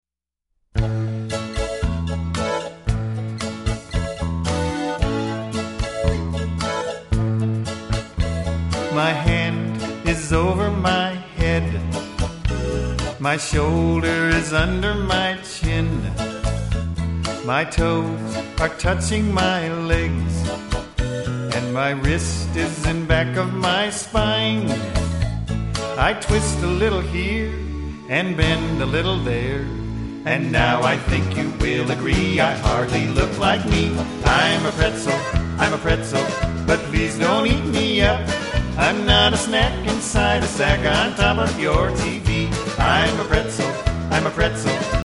Listen to a sample of this song